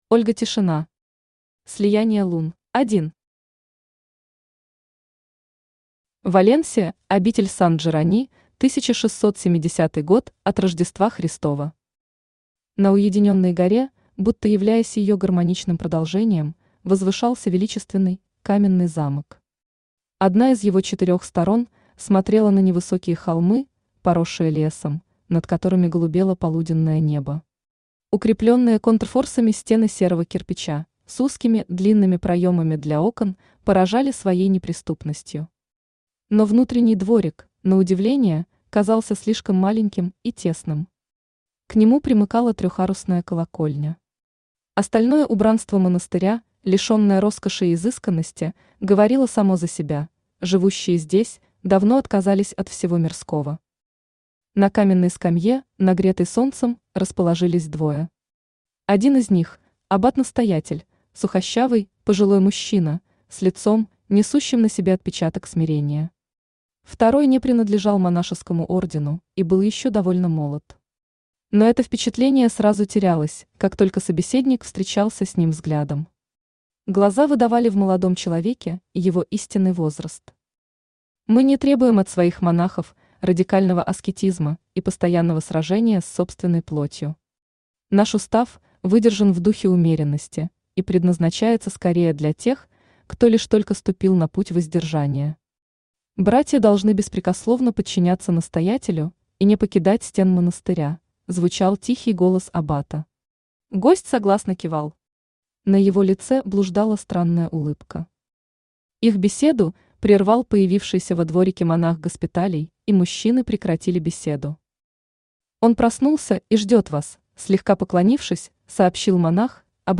Аудиокнига Слияние Лун | Библиотека аудиокниг
Aудиокнига Слияние Лун Автор Ольга Тишина Читает аудиокнигу Авточтец ЛитРес.